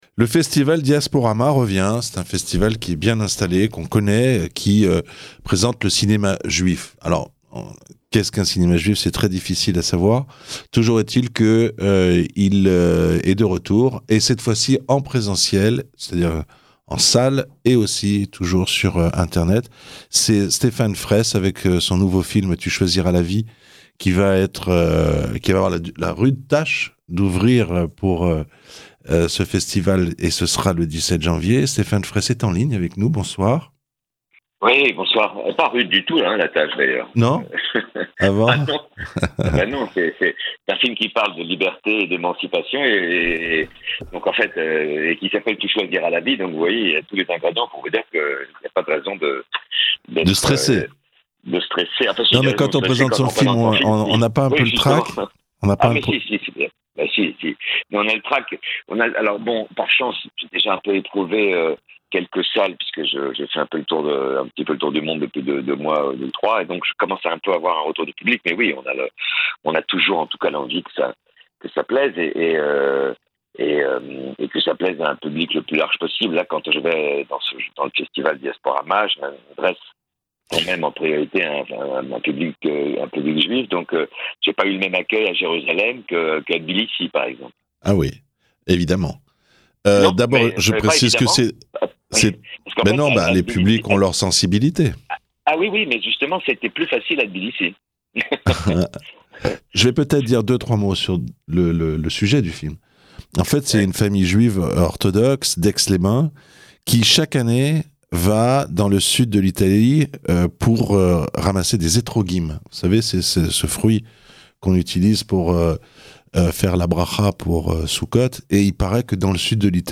Ecoutez l'interview de Stéphane Freiss